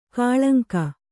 ♪ kāḷaŋka